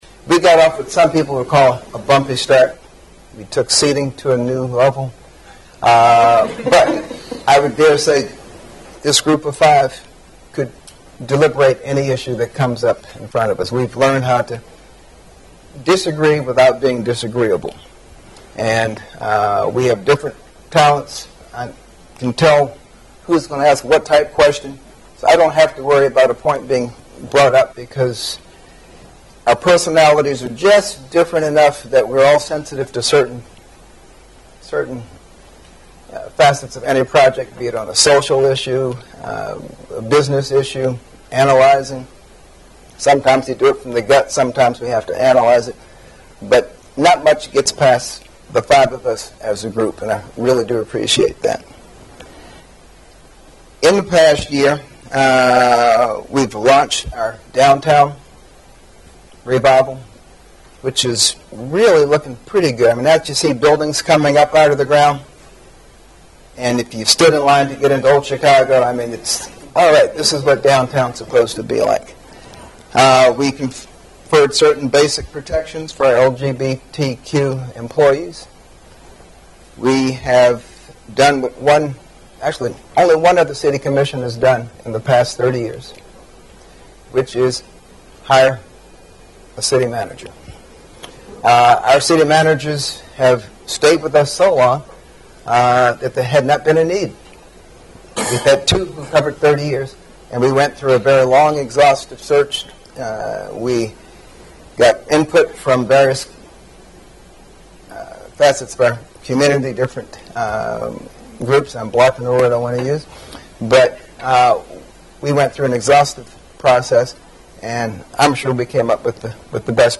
After being handed the gavel the new mayor also spoke. Davis talked about what the city commission has accomplished over the past couple of years, and looked to the future as well.